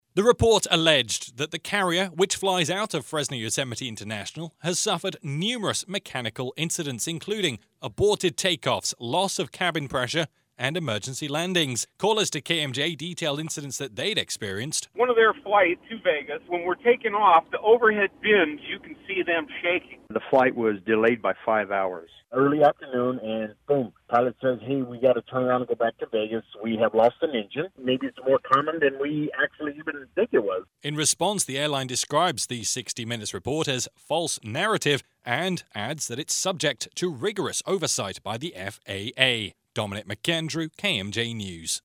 Callers to KMJ described incidents of engine failure, significant delays, and shaking inside the cabin during normal conditions.